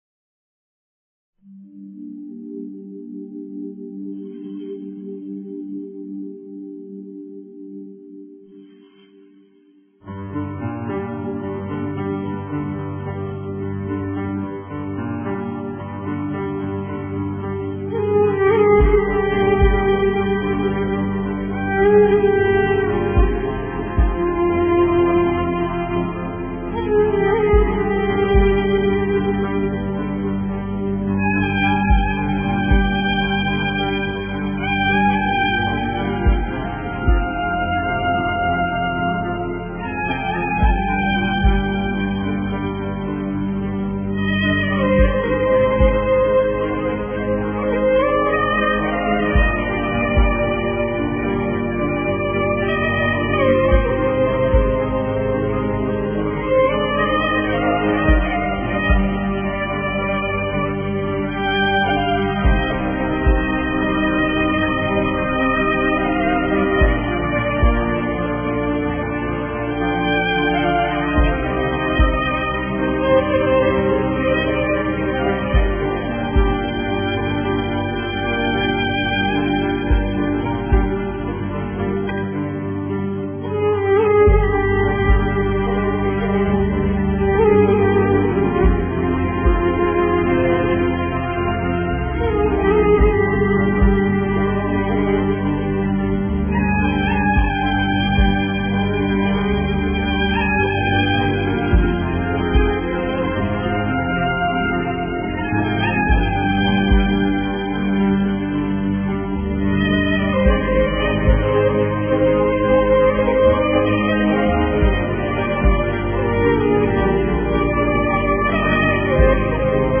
اروع موسيقة حزينة